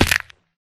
Sound / Minecraft / damage / fallbig2